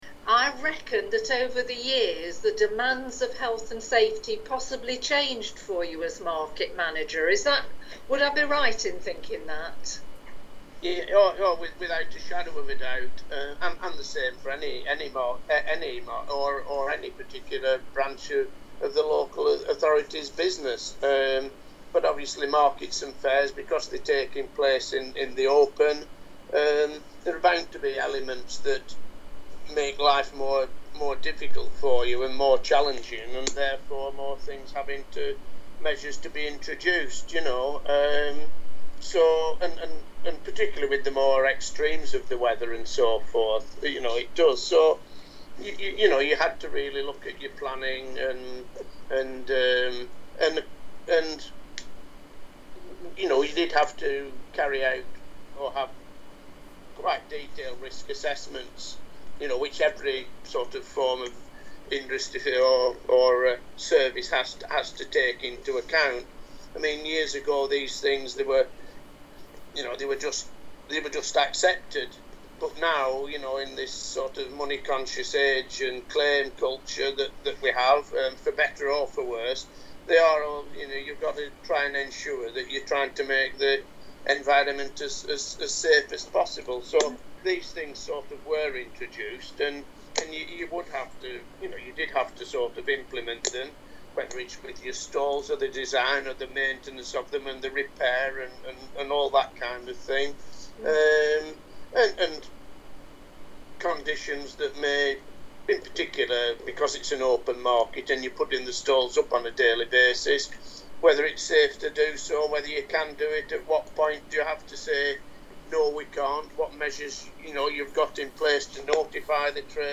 Oral History Interviews – Market
Listen below to audio clips taken from interviews conducted by our volunteers with the community reflecting on 800 years of Loughborough’s market.